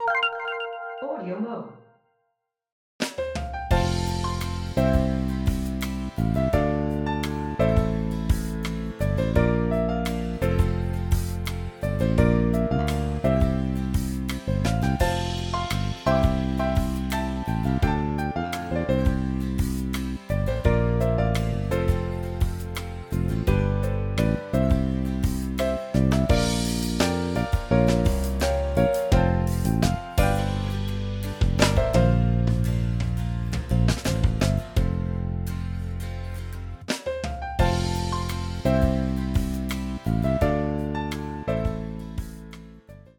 This one is rather unimaginatively called 2020-09-12, but I prefer to call it Cheap Korgscore. I was playing around with NKS mappings to the Korg Triton plug-in and created this thing in the process. It tries to sound like some epic intro that you might get while introducing a film or major character, with just two patches. One is a combi which has a bass drum and some low brass and maybe piano that I use to mark my current note and root of the chord, as well as a top of a full string section. The other is some kind of brass thing which does most of the melodic stuff for the majority of the jingle, but turns into a build detail at the end.